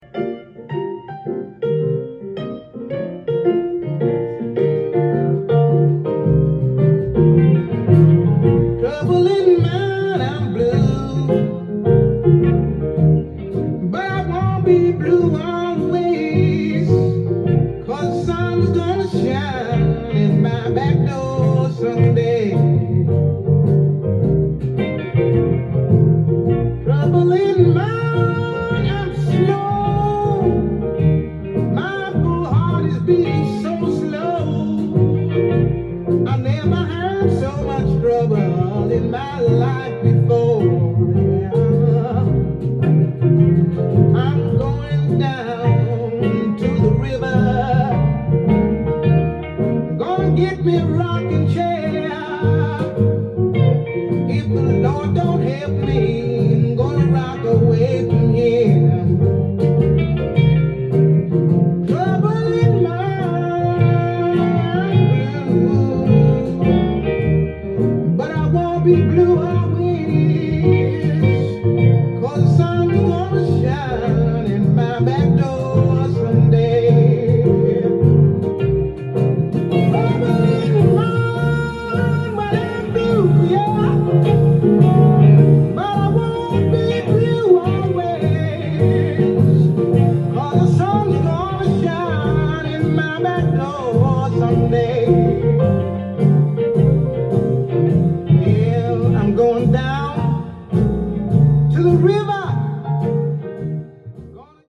ジャンル：JAZZ-VOCAL
店頭で録音した音源の為、多少の外部音や音質の悪さはございますが、サンプルとしてご視聴ください。